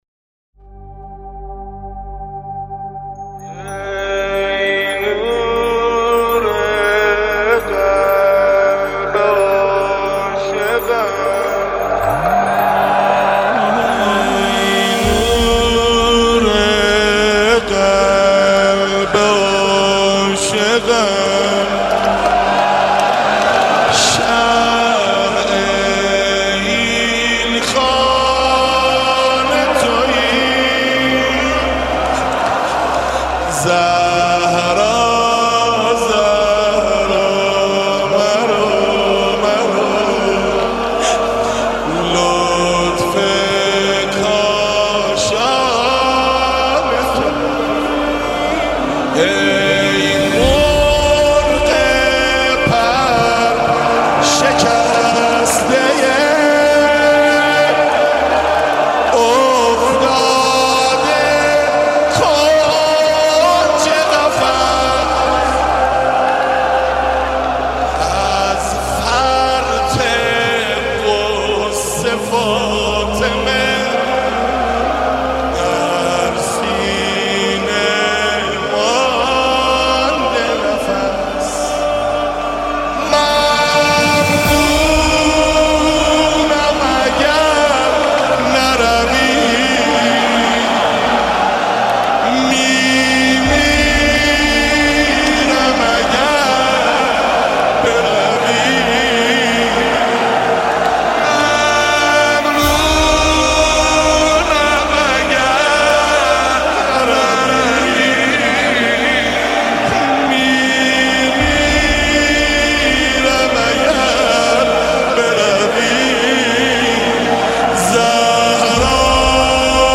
• نوحه و مداحی